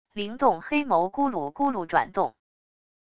灵动黑眸骨碌骨碌转动wav音频